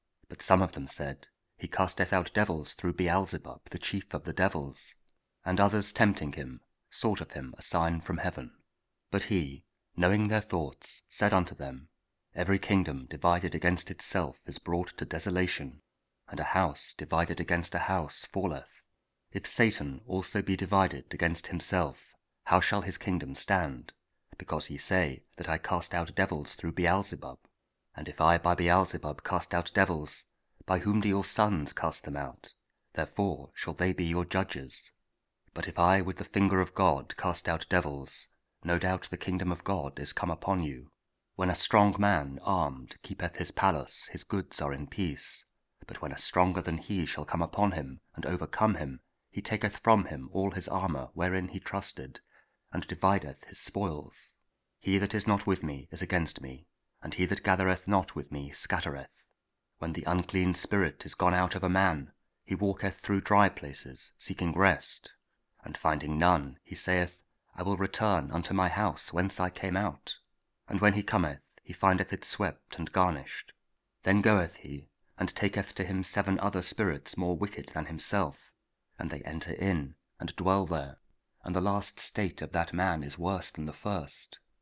Luke 11: 15-26 – Week 27 Ordinary Time, Friday – also Lent Thursday 3 – partial( King James Audio Bible KJV, Spoken Word – King James Version)